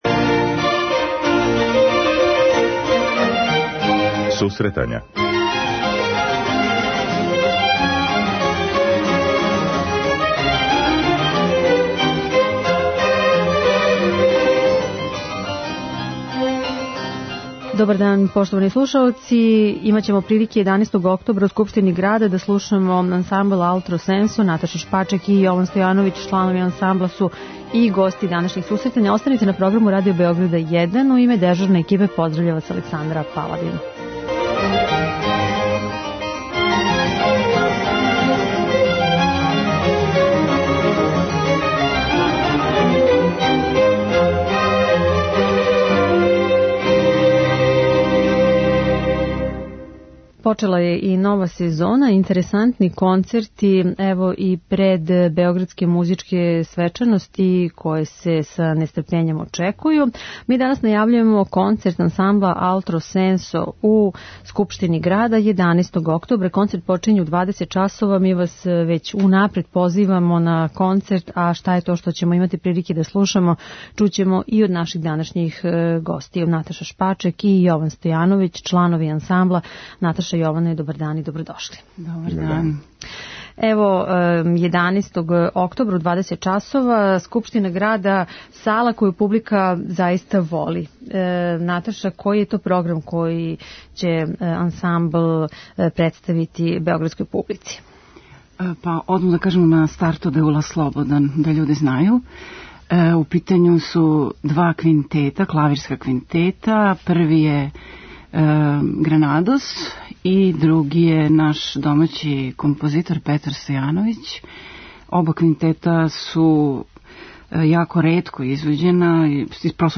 преузми : 9.87 MB Сусретања Autor: Музичка редакција Емисија за оне који воле уметничку музику.